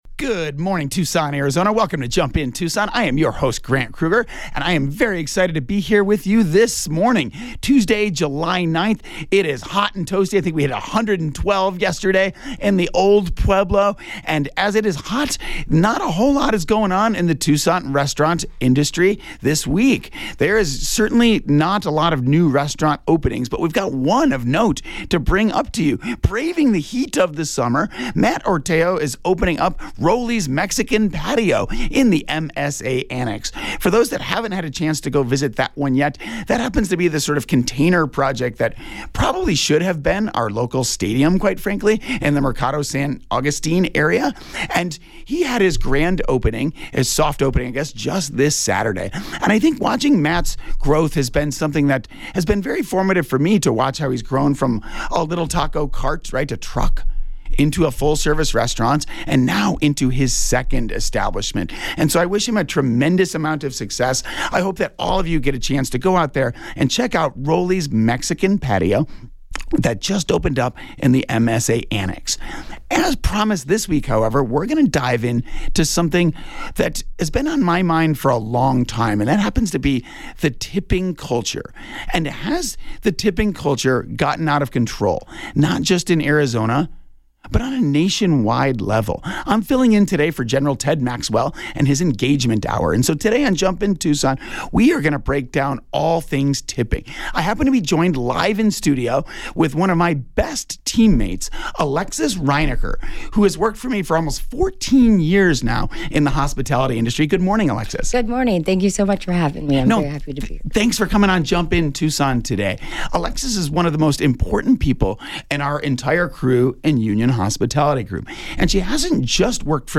Based on the 7/9/24 Jump In Tucson episode on KVOI-1030AM in Tucson, AZ.